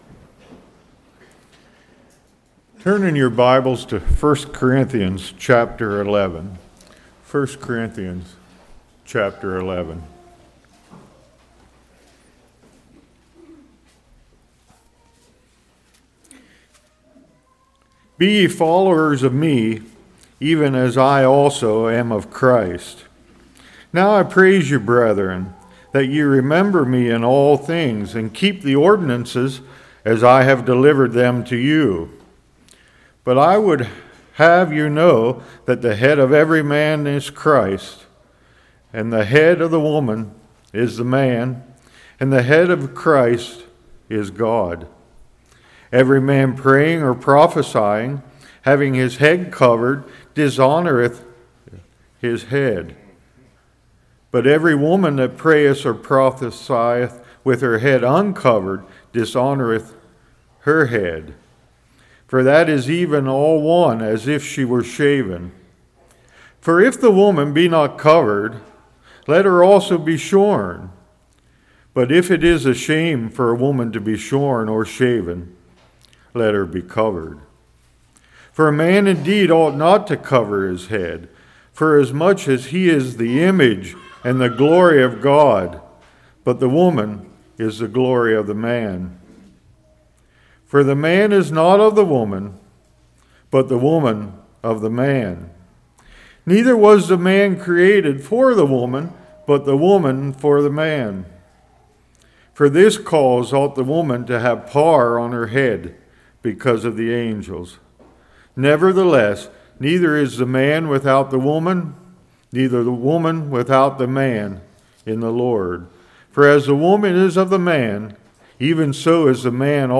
1 Corinthians 11:1-34 Service Type: Morning Will You Still Do Anything For Jesus?